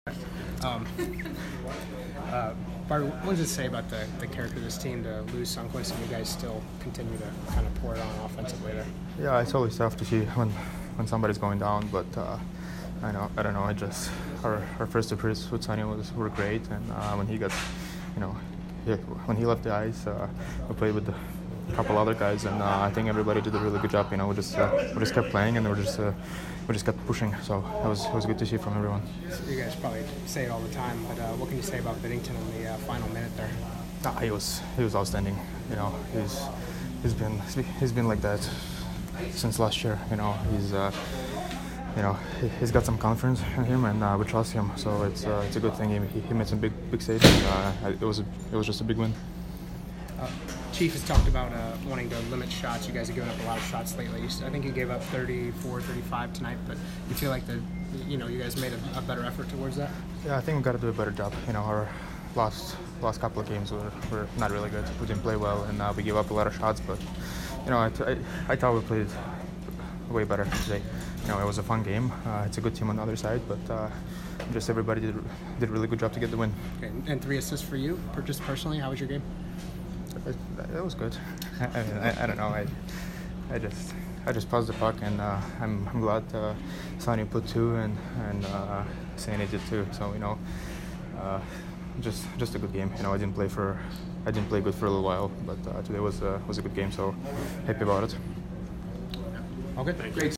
Barbashev post-game 11/27